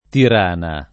vai all'elenco alfabetico delle voci ingrandisci il carattere 100% rimpicciolisci il carattere stampa invia tramite posta elettronica codividi su Facebook Tirana [ tir # na ] top.
— alb. Tiranë [ tir # në ] (con l’art., Tirana [ tir # na ])